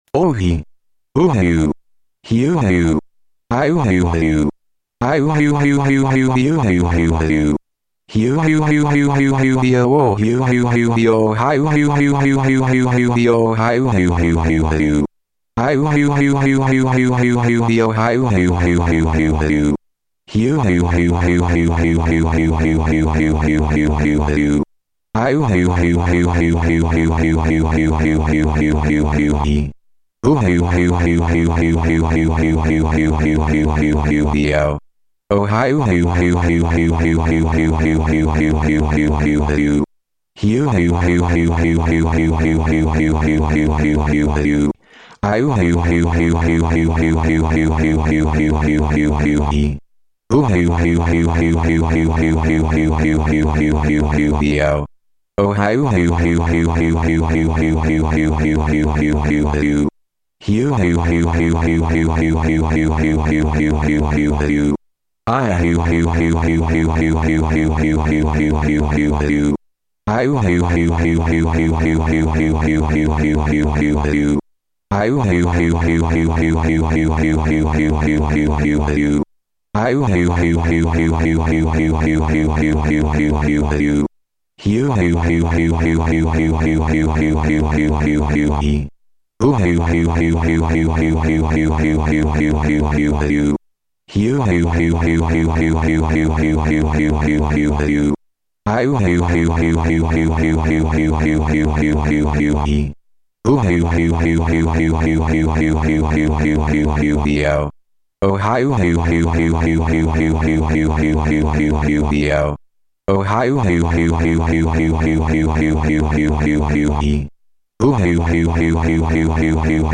Dramatic reading